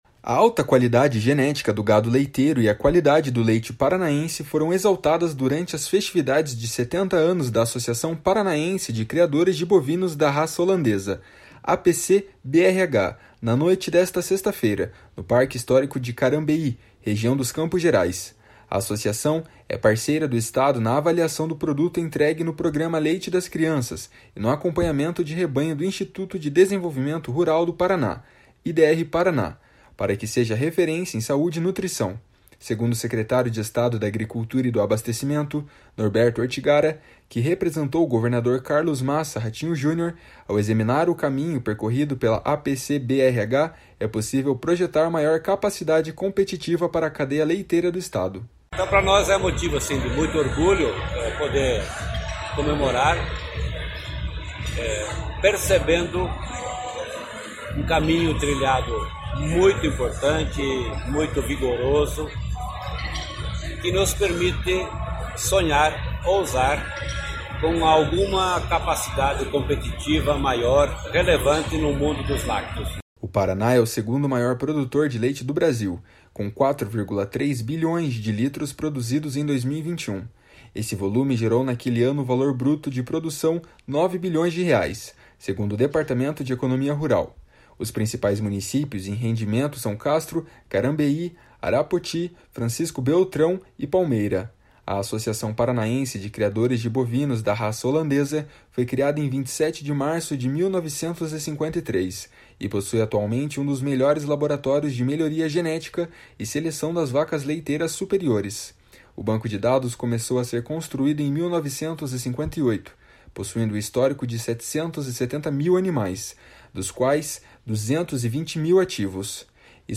// SONORA NORBERTO ORTIGARA //